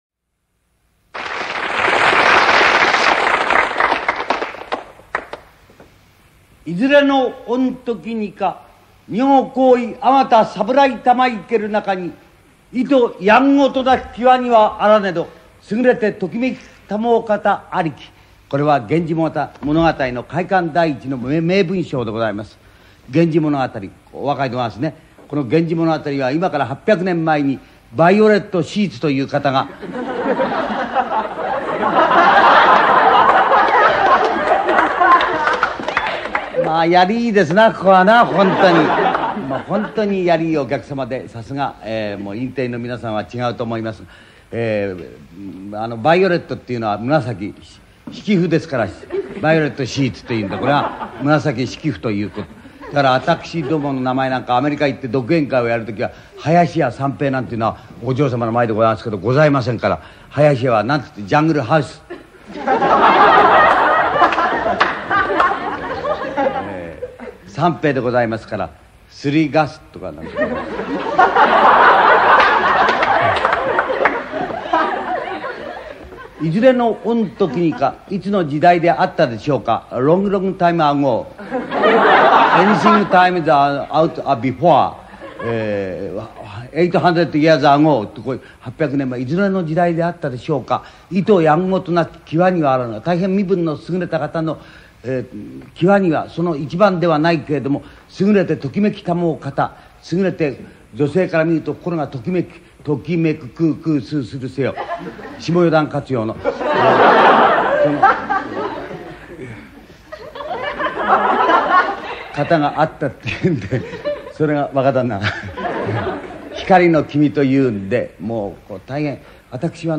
源氏物語の正しい音読の仕方は、おおかた次の通り。
てんてん、あるいは、ちょんちょん、が付された濁音は、文節の頭以外において、鼻音　ŋ　を先行させて発音される。
→　源氏物語の音読におけるハ行転呼、文節の頭以外での「ウァ、ウィ、ウ、ウェ、ウォ」には諸説があるが、当サイトでは、独断的に、ハ行転呼は避け、ハ行はハ行で読む。ただし、助詞「は」「へ」に関してのみ「ウァ」「ウェ」と読む
大きな「や、ゆ、よ」として、独立した音節で読む。
伸ばして読む。例、名「なあ」、歯「はあ」
高低アクセントは関西弁に準ずる。